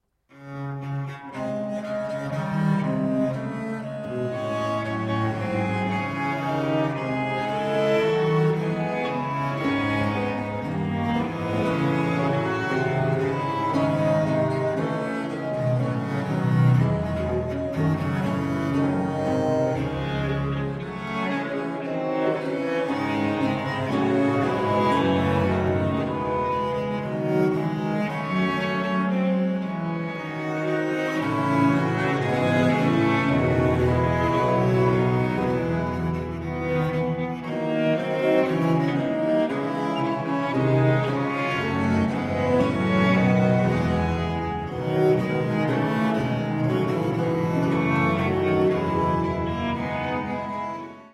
• Genres: Baroque, Renaissance
Canzona a6 in d
violin and viola
viola da gamba
violoncello
theorbo and guitar
organ and harpsichord
Recorded at the Old Meeting House, Francestown, NH
Baroque string ensemble ACRONYM is dedicated to giving modern premieres of the wild instrumental music of the seventeenth century.